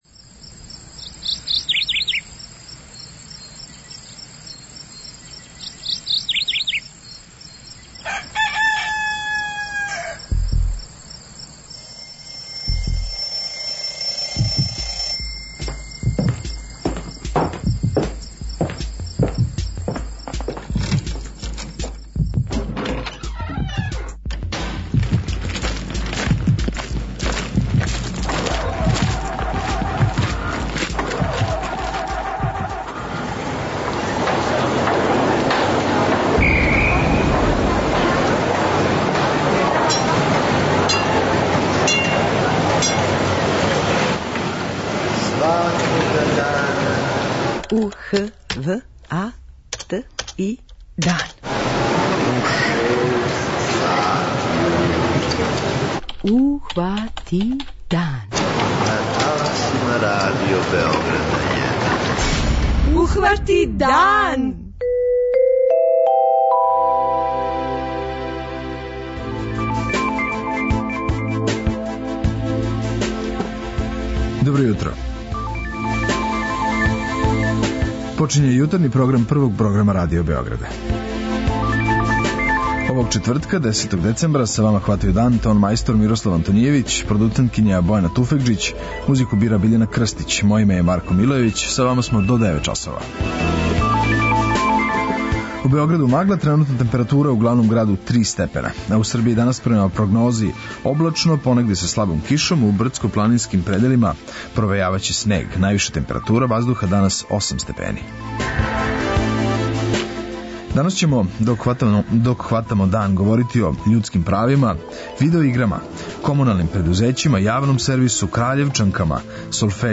У госте нам долази део екипе светских првака у видео играма на управо завршеном Интернационалном шампионату еСпорта у Јужној Кореји. Настављамо серијал о раду комуналних предузећа и о изменама које се очекују доношењем Закона о комуналним услугама.
преузми : 43.14 MB Ухвати дан Autor: Група аутора Јутарњи програм Радио Београда 1!